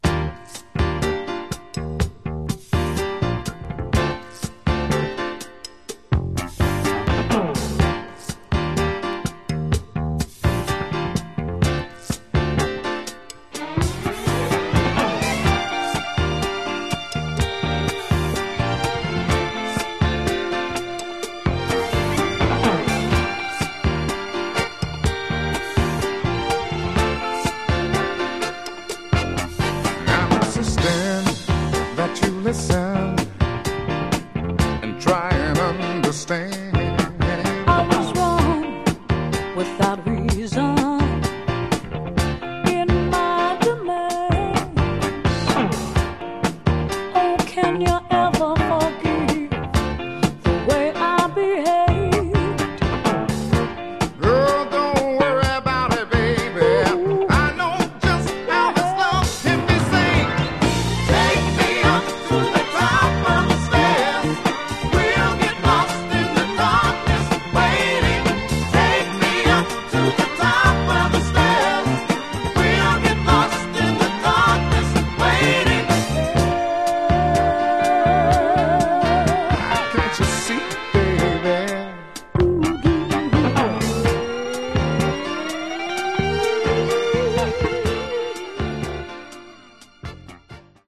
The A side, by the way, is edited from a 4:38 album track.